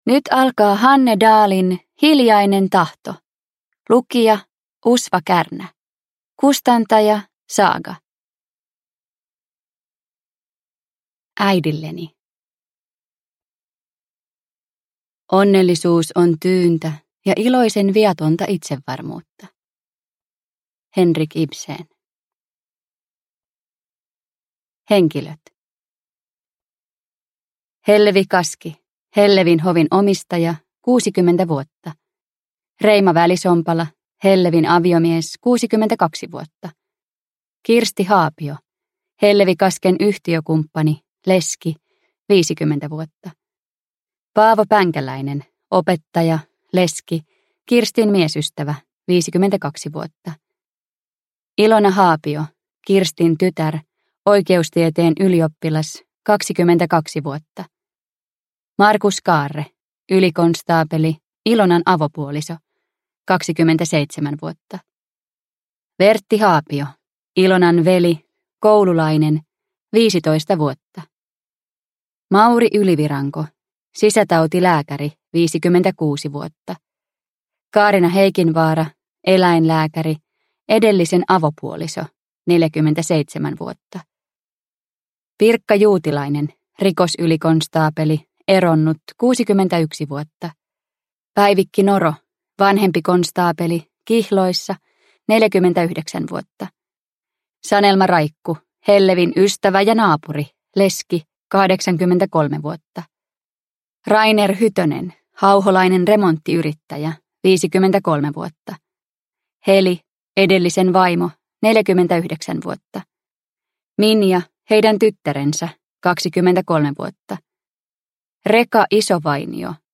Hiljainen tahto (ljudbok) av Hanne Dahl